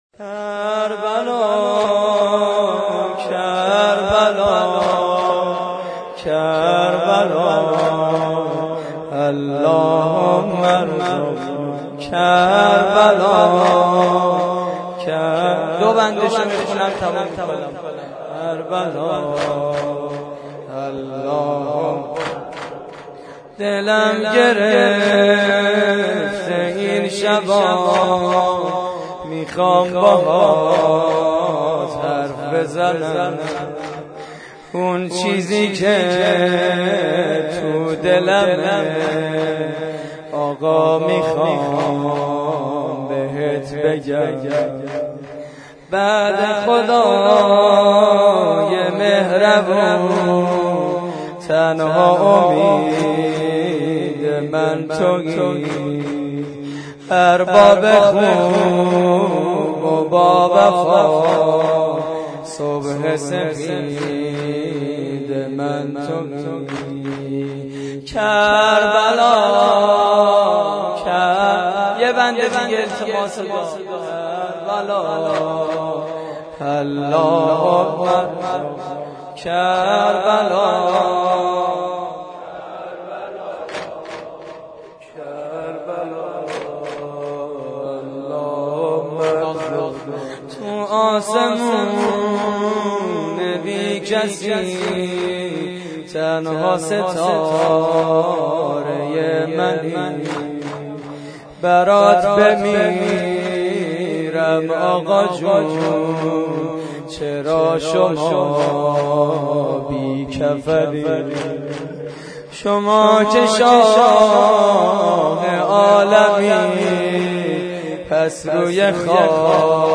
واحد: کربلا اللهم الرزقنا